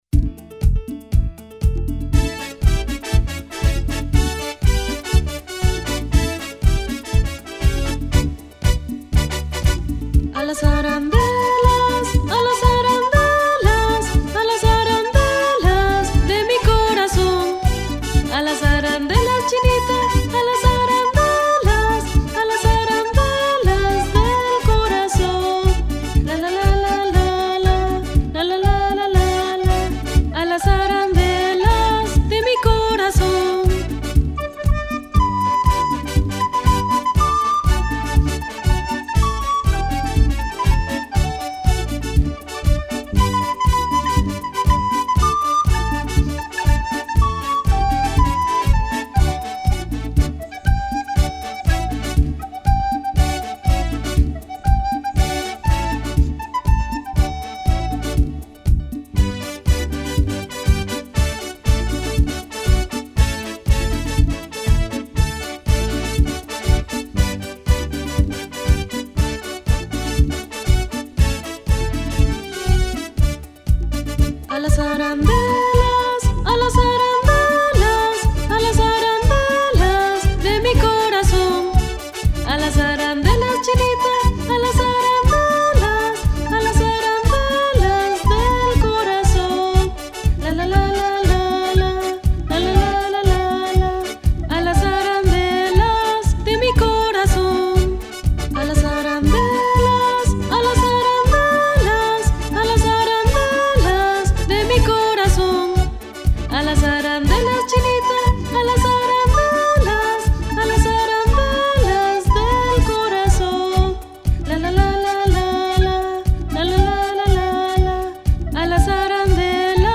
Aguinaldo tradicional dominicano